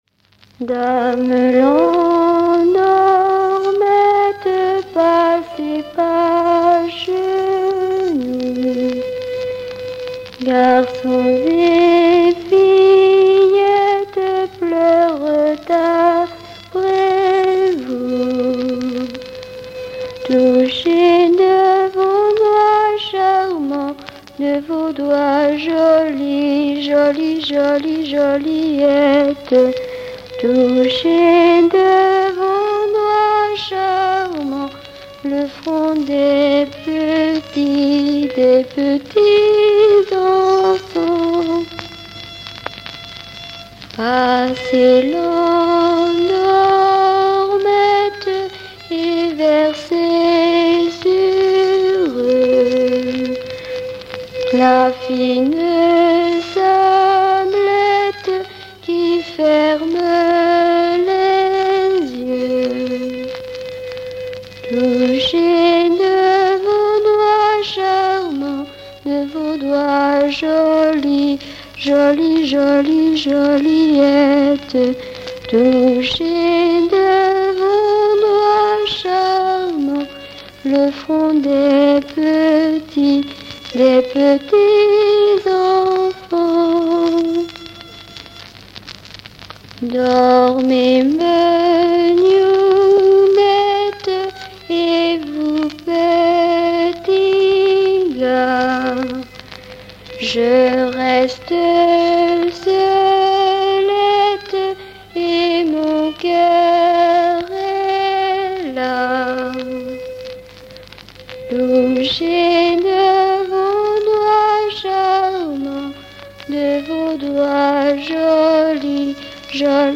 berceuse
Pièce musicale inédite